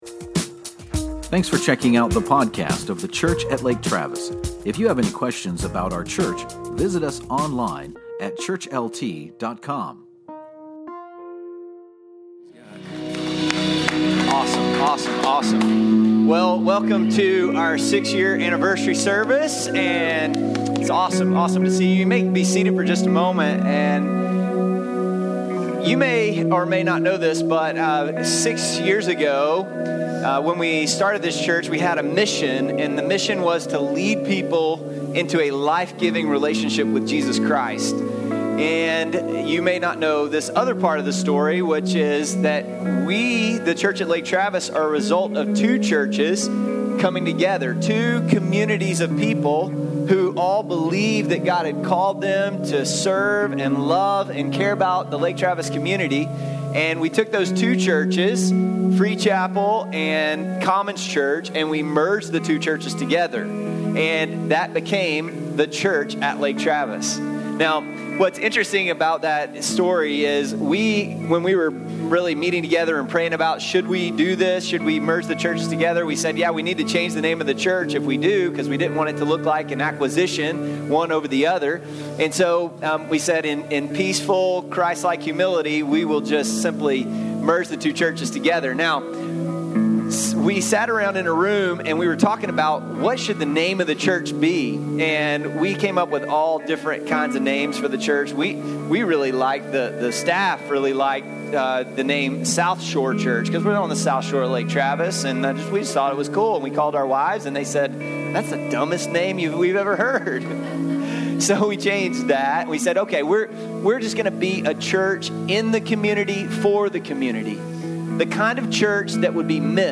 On our 6th anniversary, we spent some time reflecting on God’s goodness over the last six years. The first 7 minutes and 37 seconds of this recording is that time of reflection on a snapshot of what God has done in leading people into a life-giving relationship with Jesus Christ!